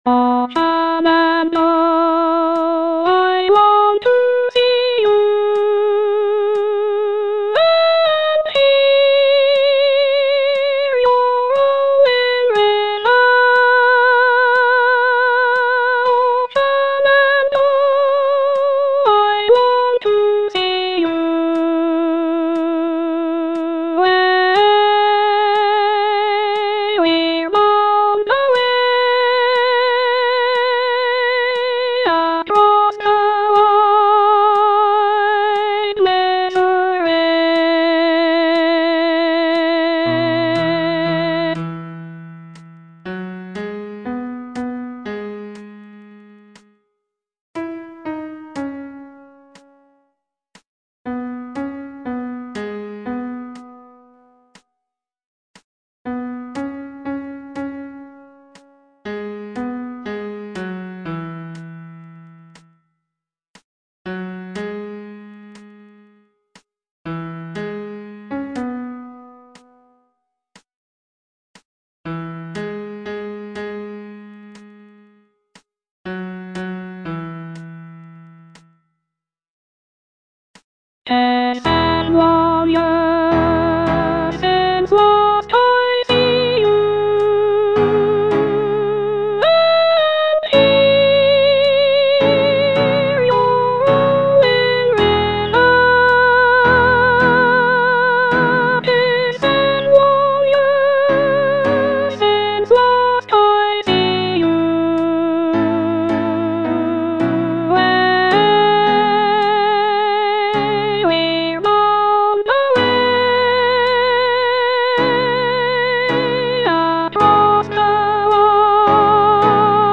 Soprano I (Voice with metronome)